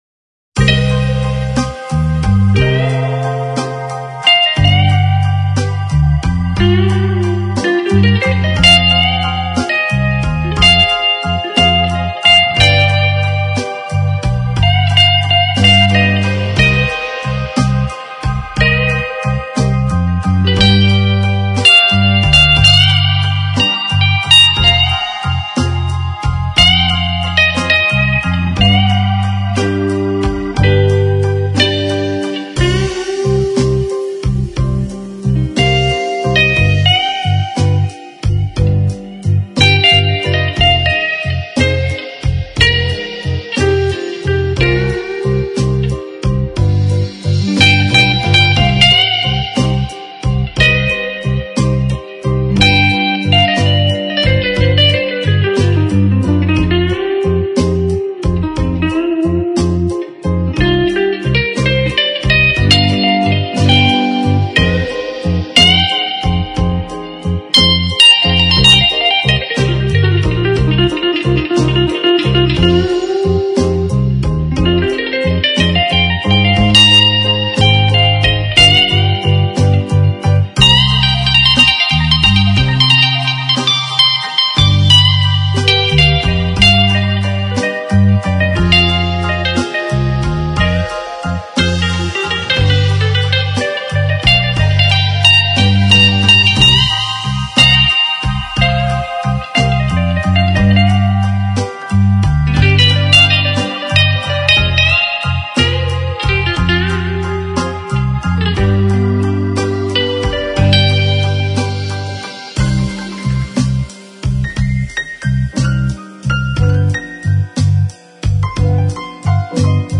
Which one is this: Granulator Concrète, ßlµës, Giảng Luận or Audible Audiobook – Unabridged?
ßlµës